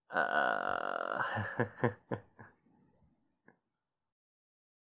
Male laugh (uncomfortable)
haha heh hehe laugh laughing male man uncomfortable sound effect free sound royalty free Funny